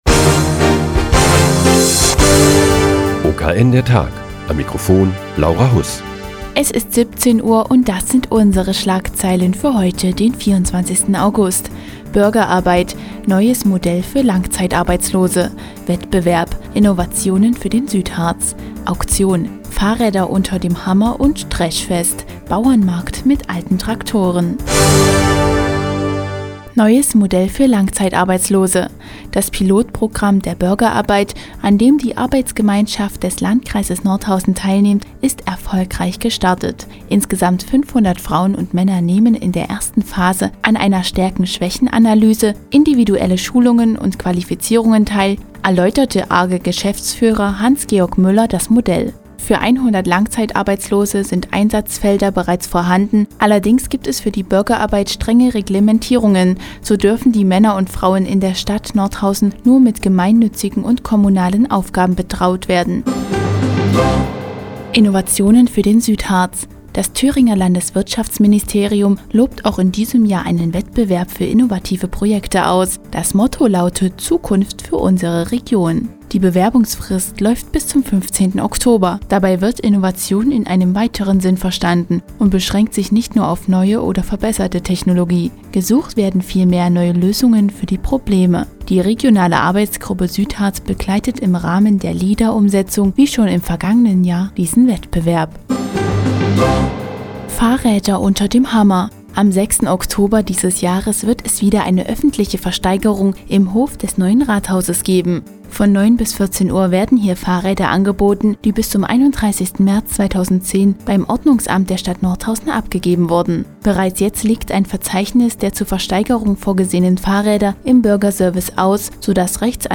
Die tägliche Nachrichtensendung des OKN ist nun auch in der nnz zu hören. Heute geht es um ein neues Modell für Langzeitarbeitslose und eine öffentliche Fahrrad- Versteigerung im Hof des Neuen Rathauses.